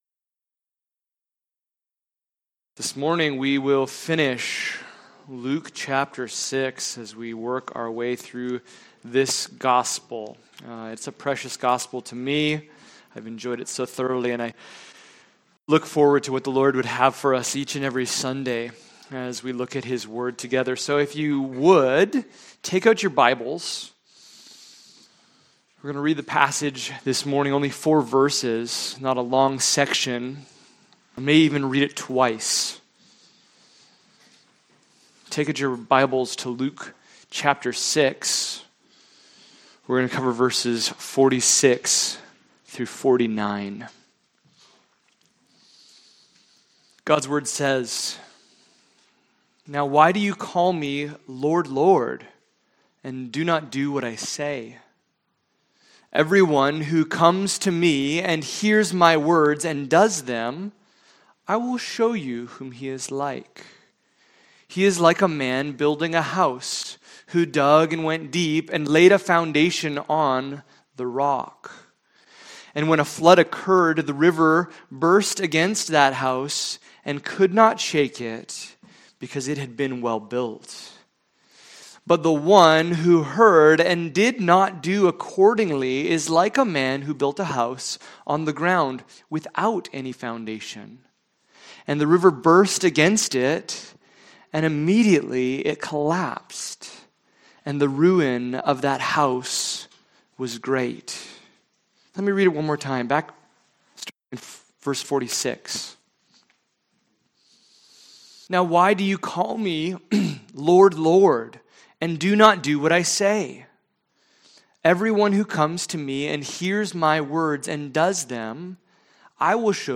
Luke 6:46-49 Service Type: Sunday Morning « The Problem of Hypocrisy The Kingdom Paradox